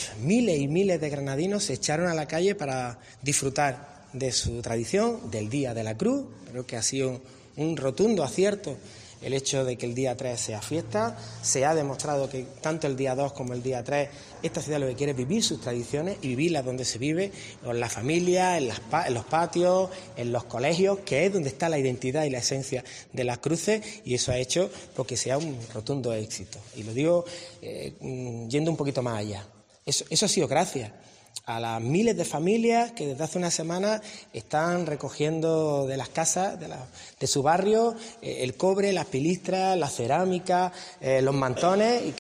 "No ha habido prácticamente ningún incidente", ha indicado Cuenca, a preguntas de los periodistas este jueves, manteniendo que "después de décadas" se ha "recuperado el Día de la Cruz" en una ciudad que "lo que quiere es vivir sus tradiciones".